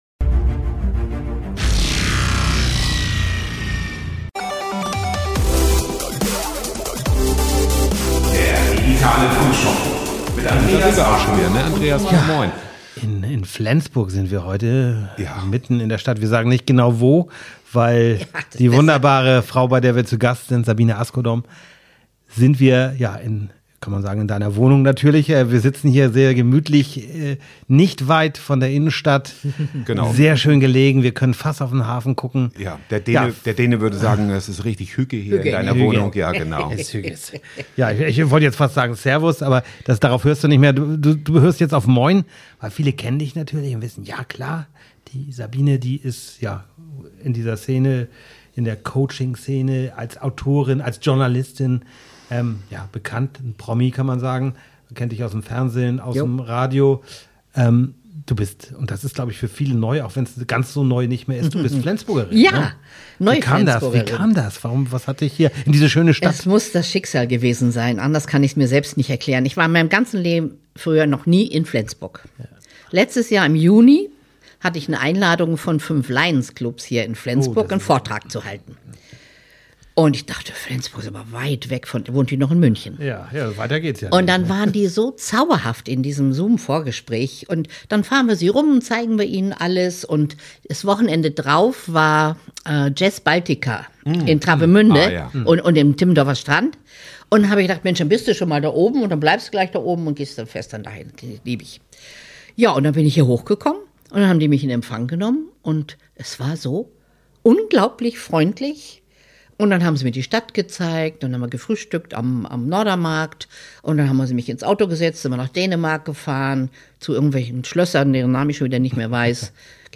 Sie haben eine ganz besondere Gästin: Sabine Asgodom – Bestsellerautorin, Business-Coach und eine der bekanntesten Persönlichkeiten der deutschen Coaching-Szene.